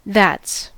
Ääntäminen
Vaihtoehtoiset kirjoitusmuodot thass Ääntäminen US Tuntematon aksentti: IPA : /ðæts/ Haettu sana löytyi näillä lähdekielillä: englanti Käännöksiä ei löytynyt valitulle kohdekielelle.